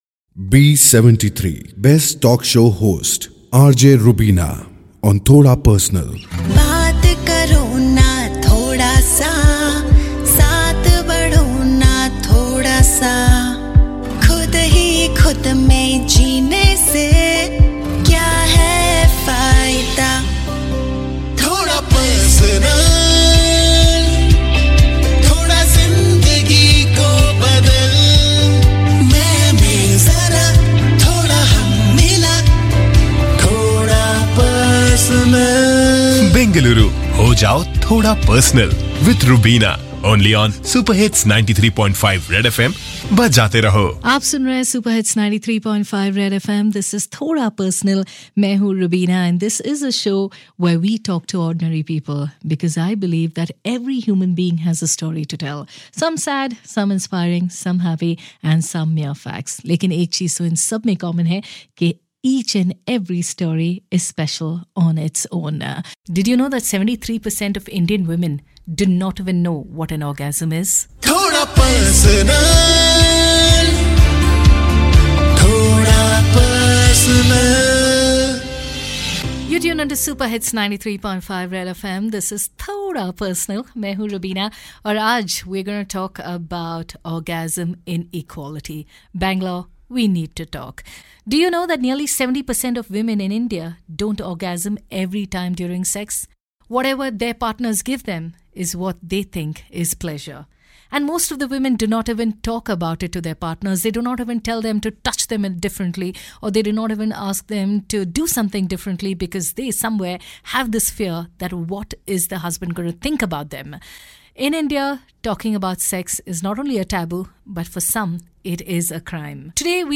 Thoda Personal translates to ‘a little personal’ in English, as the name suggests it is not just a regular radio talk show but is a show that is curated and designed to delve a tad deeper and get a little closer to the issues that truly touch a human soul.